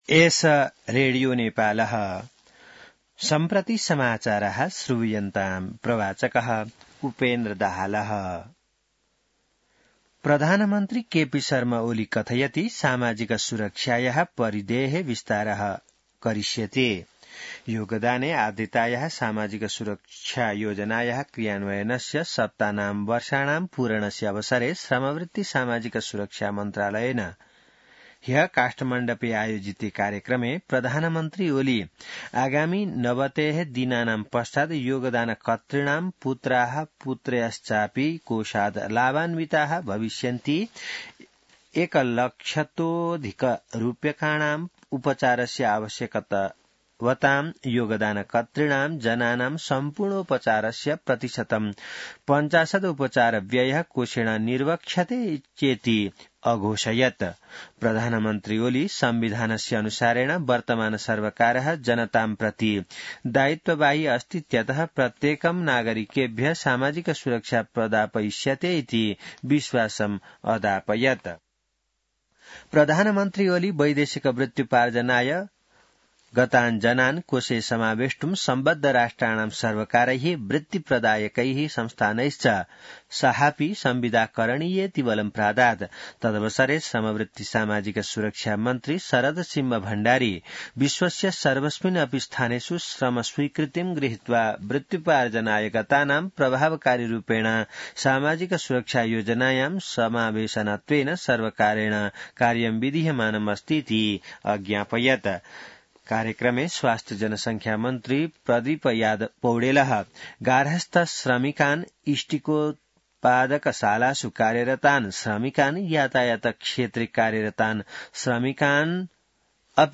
संस्कृत समाचार : १३ मंसिर , २०८१